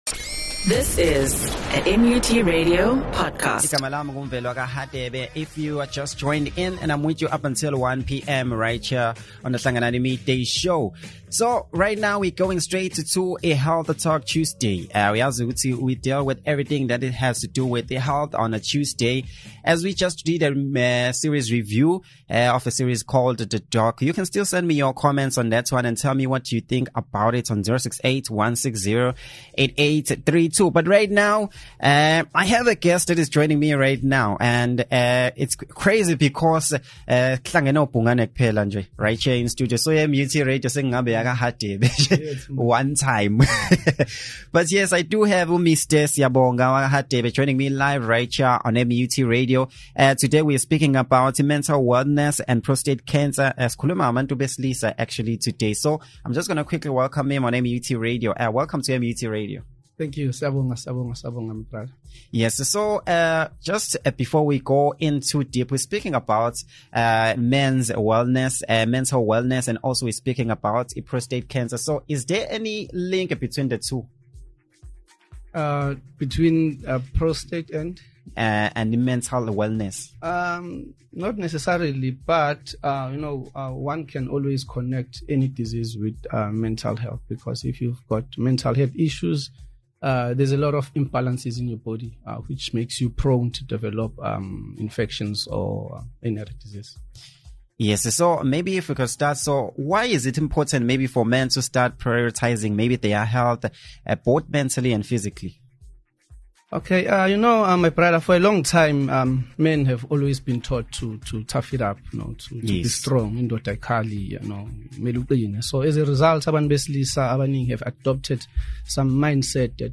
The interview concluded with a powerful message urging men to take charge of their health and reminding families and communities to actively support men in their wellness journey.